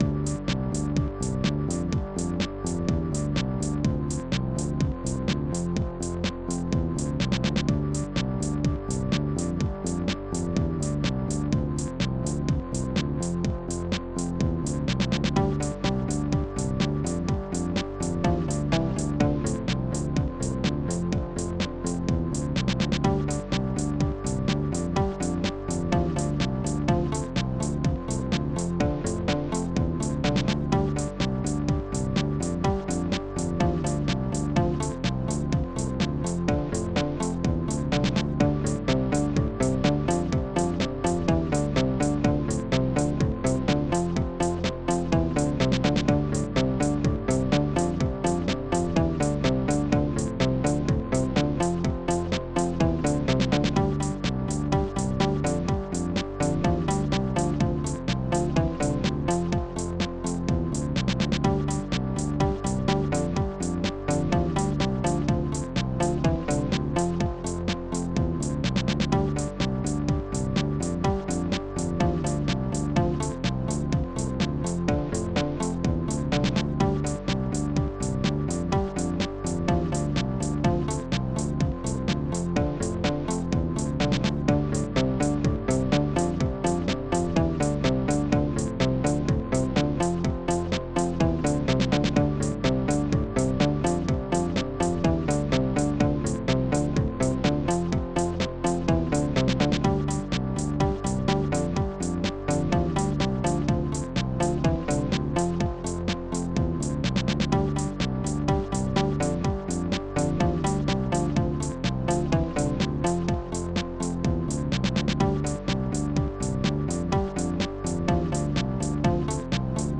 Protracker Module
Instruments gbass church snare13 bastrumma bassdrum6 guitar7